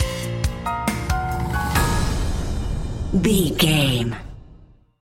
Aeolian/Minor
B♭
eerie
ominous
piano
synth
percussion